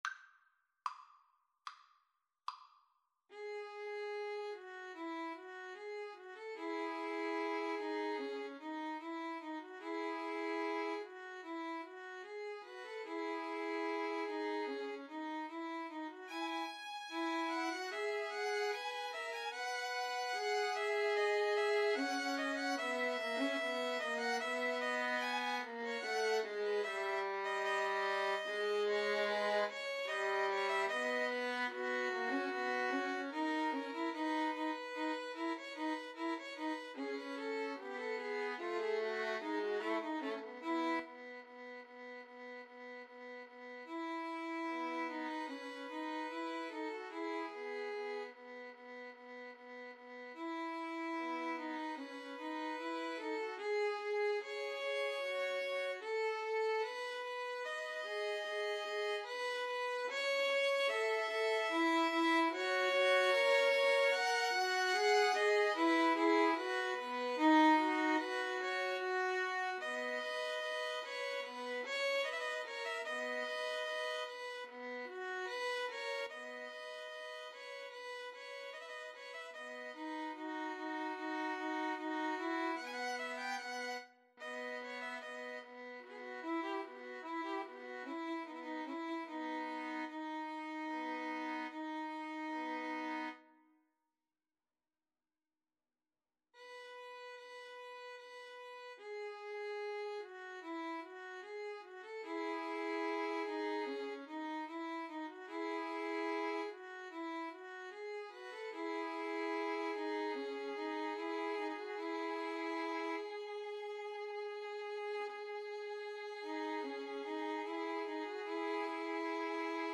Free Sheet music for Violin Trio
4/4 (View more 4/4 Music)
~ = 74 Moderato
E major (Sounding Pitch) (View more E major Music for Violin Trio )